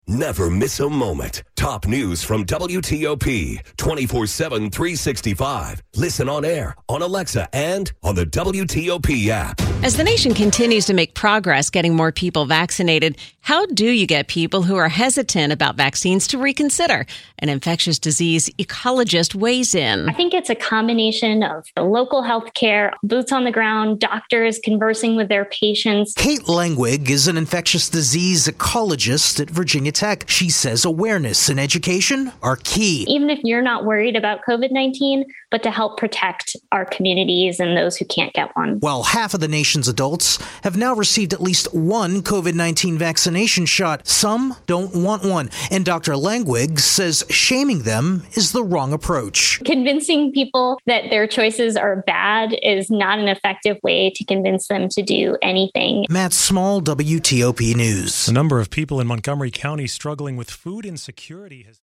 How to get those who are hesitant about vaccines to reconsider? An infectious disease ecologist weighs-in.